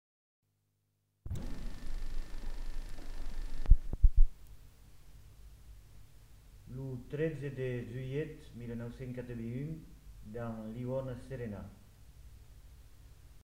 Annonce de l'enquêteur
Aire culturelle : Marmandais gascon
Lieu : Tonneins
Genre : parole